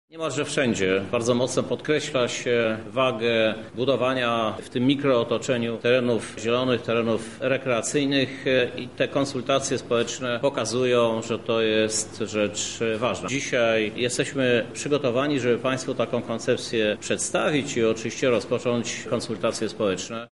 -mówi prezydent Lublina, Krzysztof Żuk.